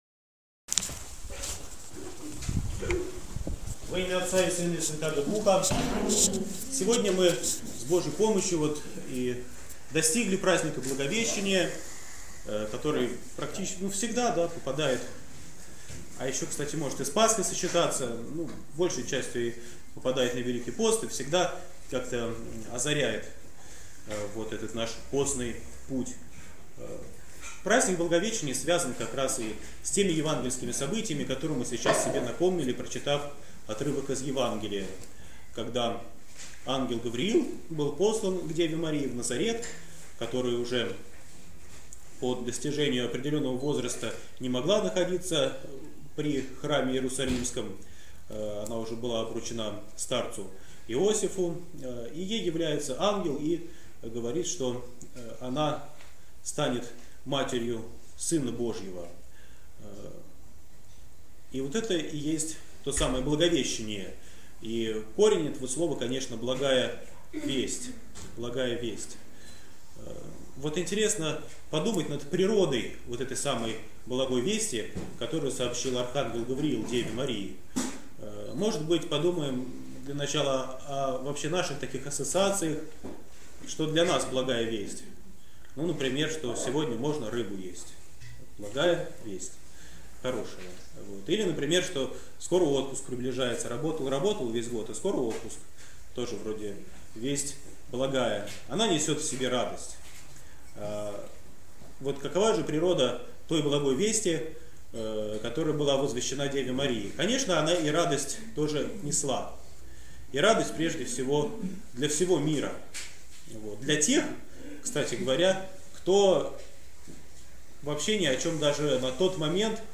Проповедь на Благовещение 2014